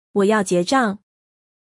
Wǒ yào jiézhàng.